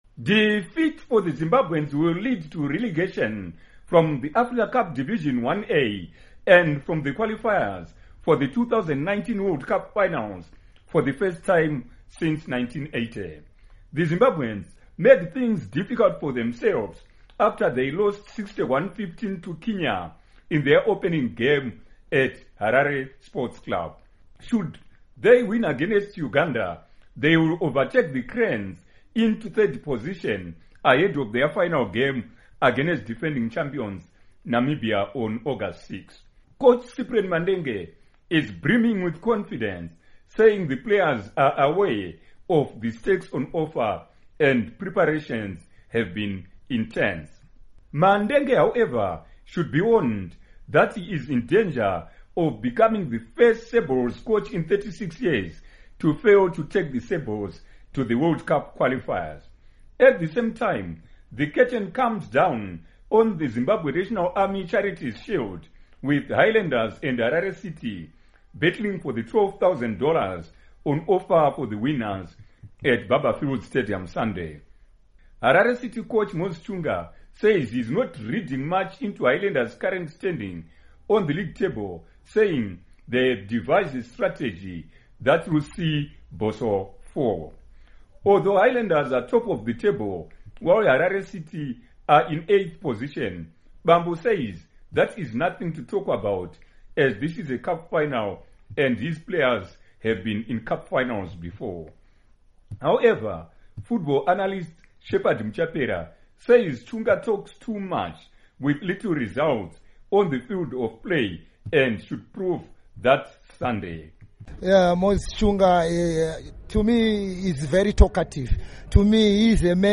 Report on Rugby, Soccer